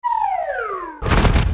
1 channel
choc tombe
B_CLIC.mp3